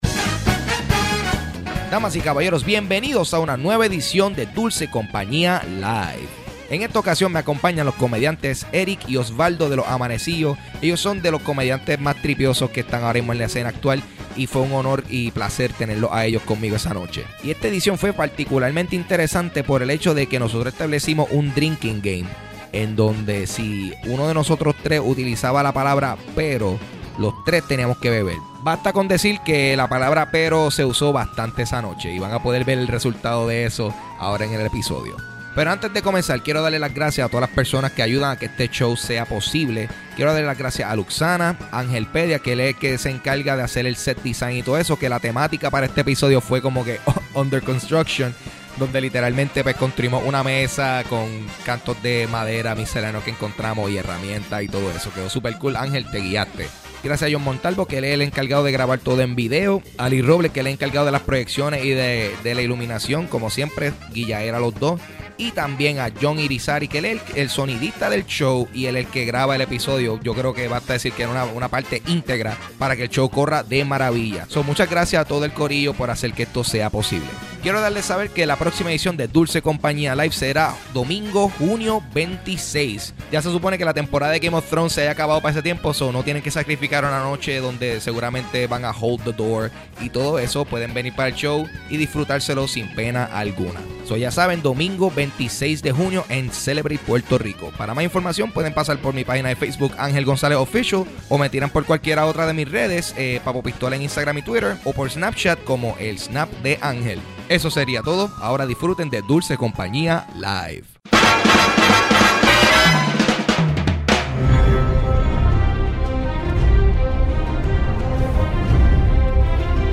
Grabado el 4 de junio de 2016 en Celebrate.